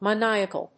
音節ma・ni・a・cal 発音記号・読み方
/mənάɪək(ə)l(米国英語)/